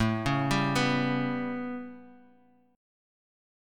A9 Chord
Listen to A9 strummed